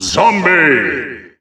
The announcer saying Zombie's name in English and Japanese releases of Super Smash Bros. Ultimate.
Zombie_English_Announcer_SSBU.wav